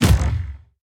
direct_hit.ogg